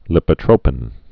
(lĭpə-trōpĭn, līpə-)